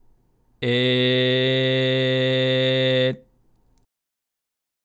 ②口を「え」の形に開けたまま、口からだけ「えー」と声を出します。
これは軟口蓋と喉の壁（咽頭壁）がくっついたことで、口のトンネルにのみ声が流れている状態です（通称「口だけ」）
※見本のグーの口からの声